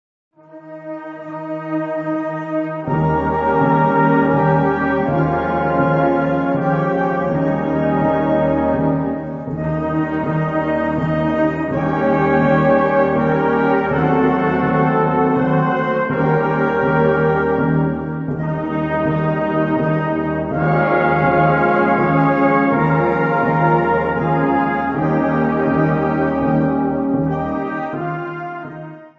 Gattung: Solo für Trompete und Blasorchester
Besetzung: Blasorchester